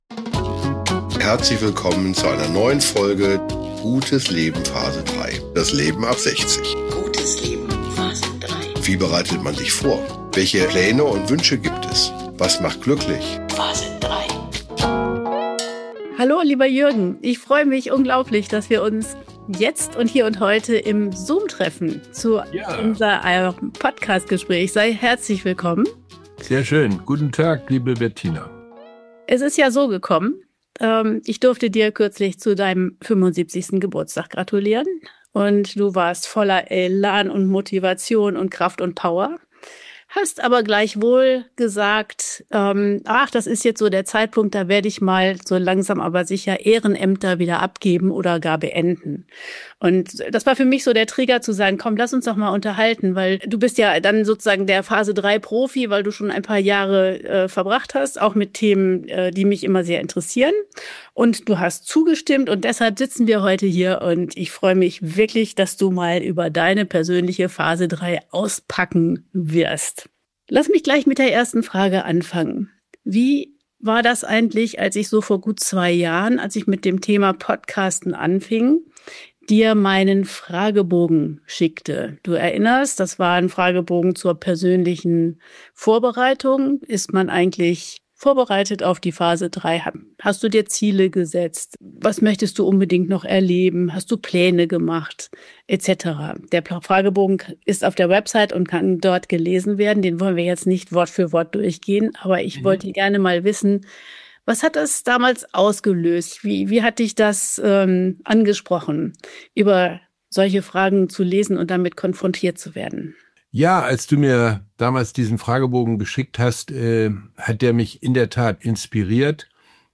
025: Ehrenamt richtig gemacht - ein Gespräch